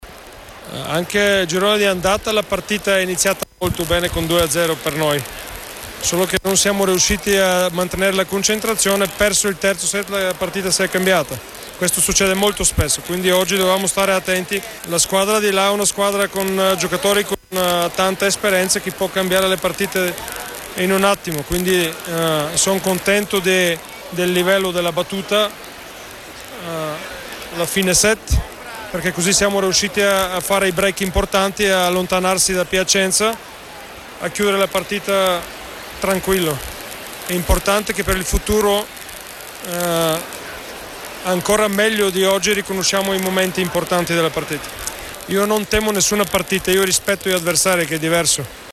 Interviste mp3